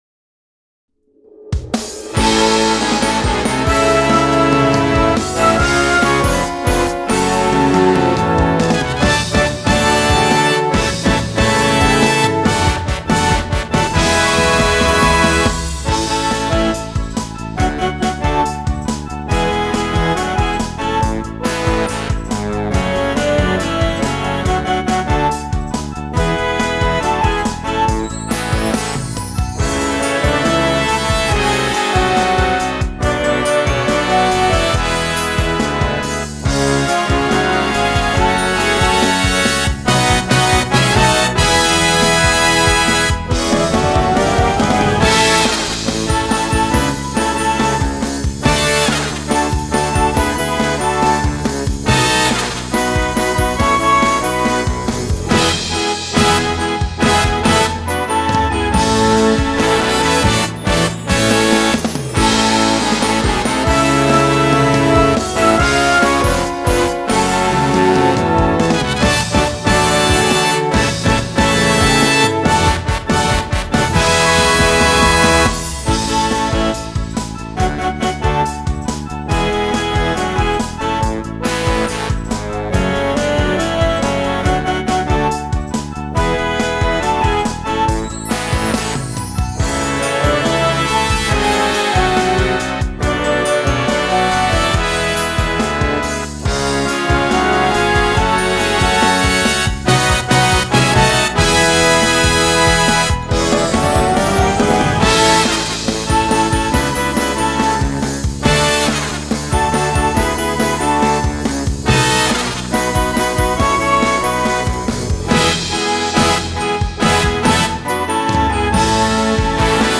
【吹奏楽】演奏イメージ音源
吹奏楽用楽譜を使用して演奏した場合のイメージ音源です